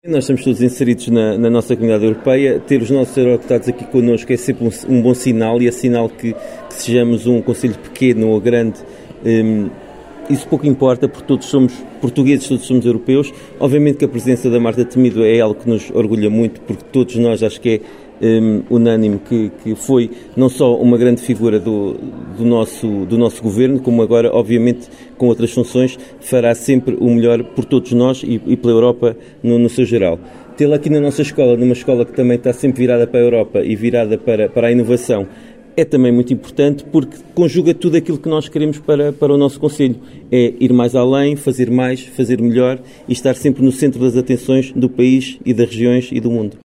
Marta Temido, em declarações à Alive FM, referiu a importância deste tipo de iniciativas, junto dos alunos e da comunidade escolar.
Paulo Marques, Presidente do Município, refere que foi um bom sinal ter a presença da Deputada Europeia Marta Temido, na Escola Secundária de Vila Nova de Paiva, “uma escola virada para a inovação…”.